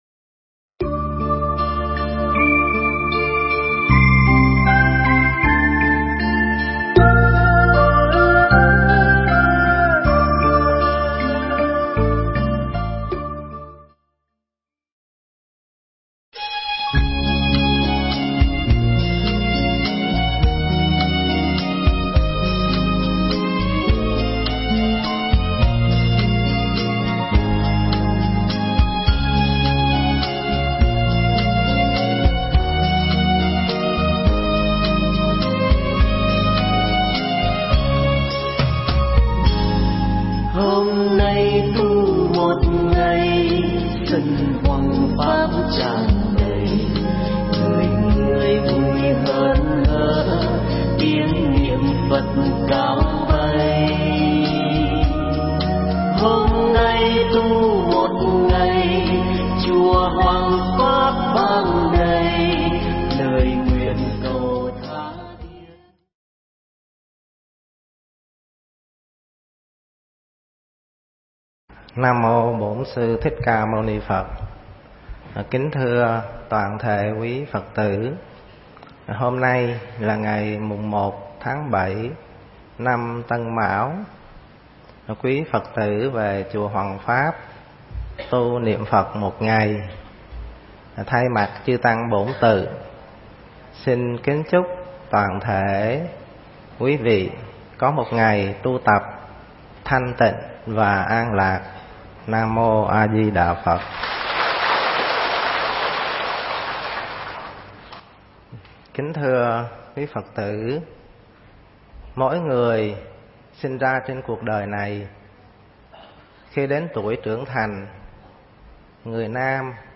Nghe Mp3 thuyết pháp Sở Trường Người Xuất Gia